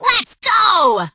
penny_start_vo_01.ogg